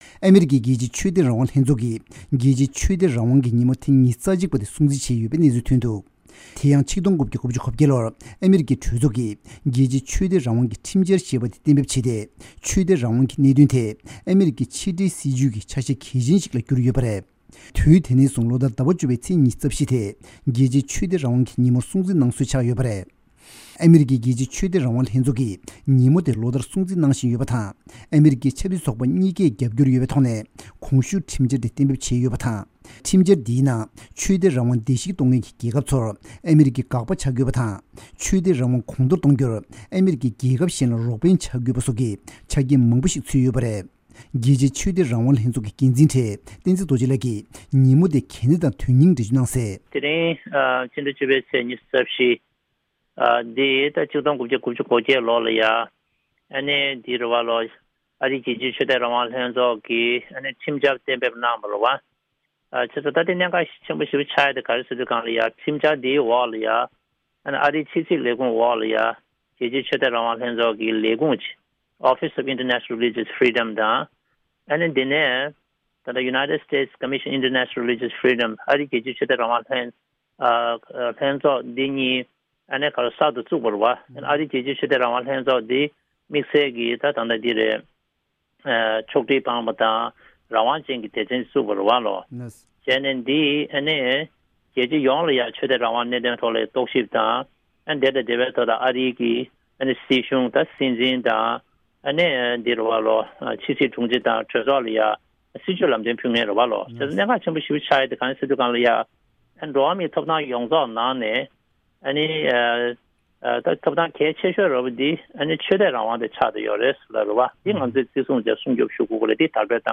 གནས་ཚུལ་སྙན་སྒྲོན་ཞུ་ཡི་རེད།